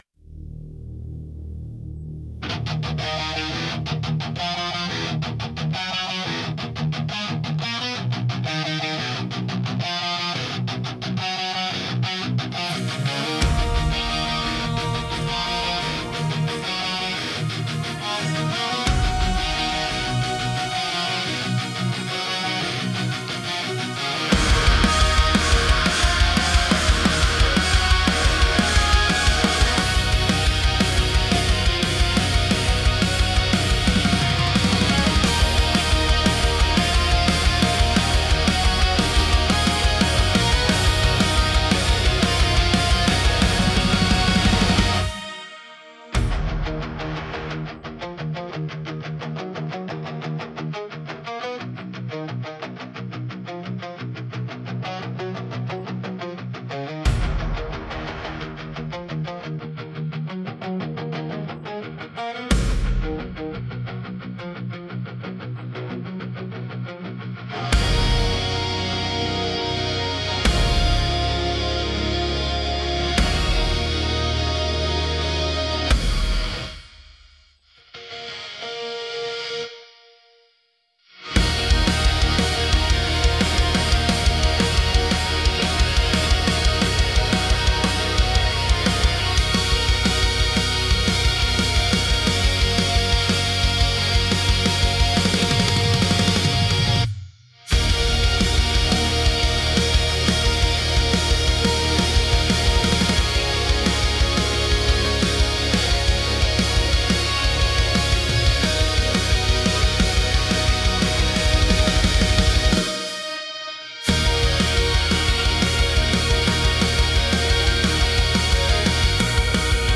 Demo Song (instrumental):